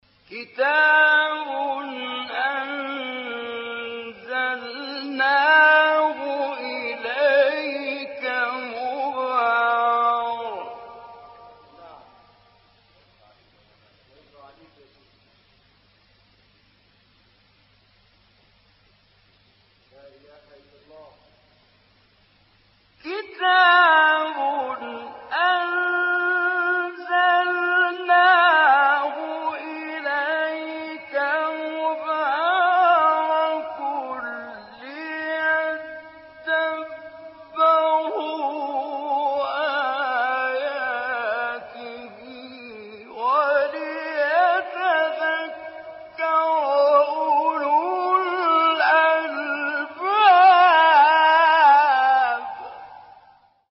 مقطعی از سوره ص از استاد حمدی زامل | نغمات قرآن | دانلود تلاوت قرآن